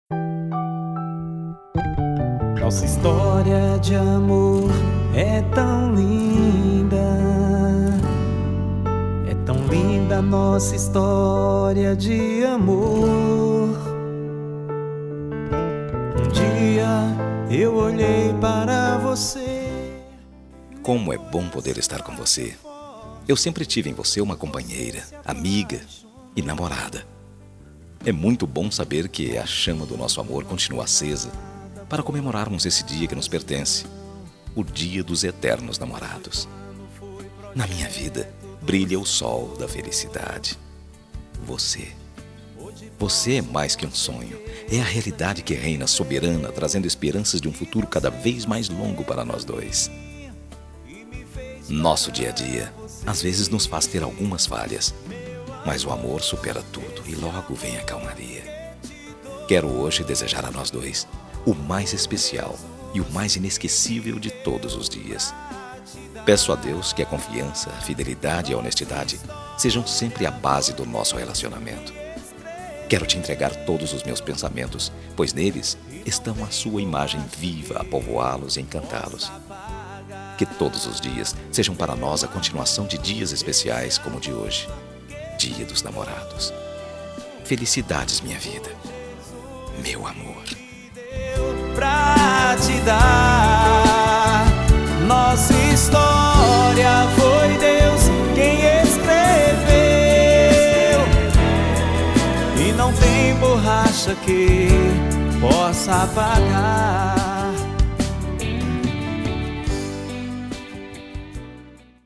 Voz Masculina
Código: 111113 – Música: Nacional Evangélica – Artista: Desconhecido